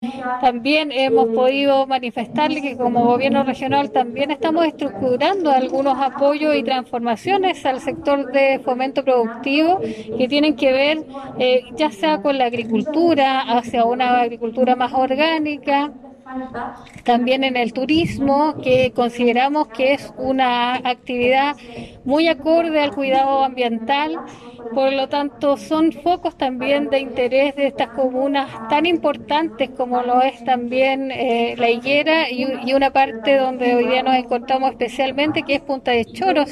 Para realizar esta mesa de trabajo y levantar información que servirá como guía para el desarrollo de proyectos que financiará el Gobierno Regional, se eligió la localidad de Punta de Choros, comuna de La Higuera, lugar donde llegó la gobernadora regional, Krist Naranjo, quien comunicó que